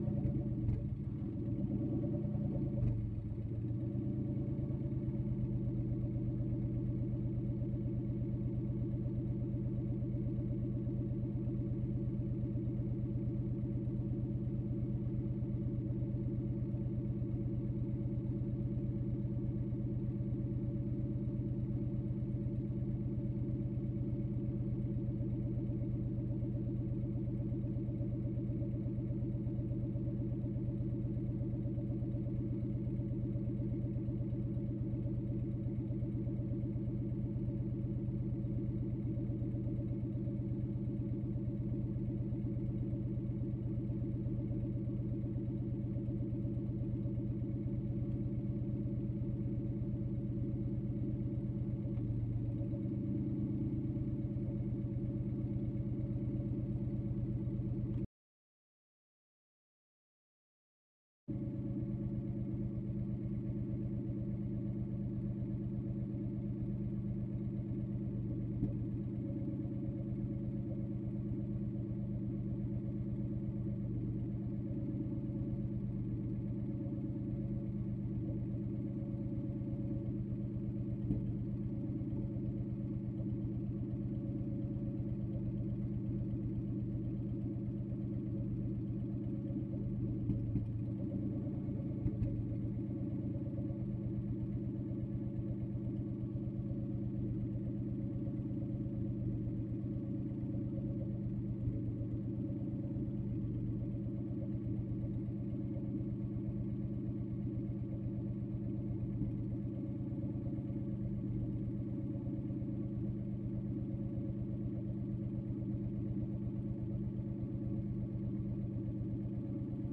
Pole Position - BMW E30 318i 1988 Street Race Modded